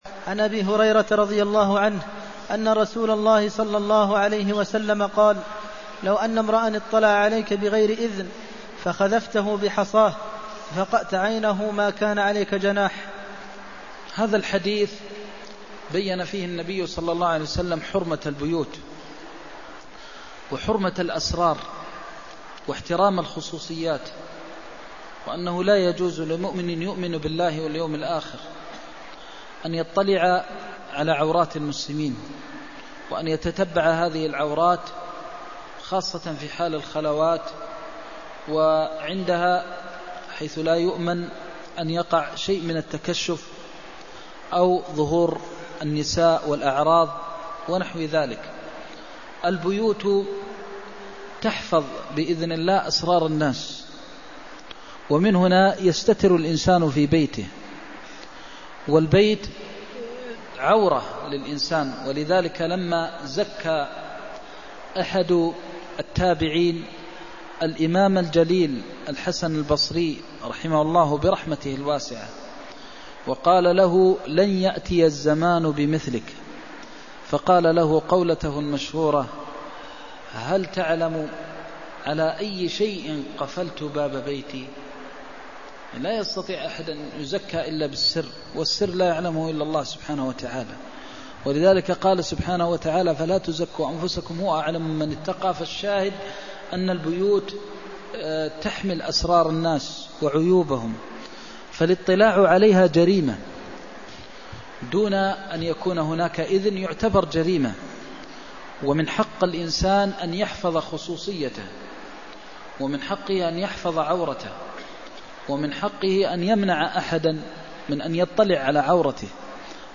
المكان: المسجد النبوي الشيخ: فضيلة الشيخ د. محمد بن محمد المختار فضيلة الشيخ د. محمد بن محمد المختار لو أن امرءا اطلع عليك بغير أذنك فخذفته (333)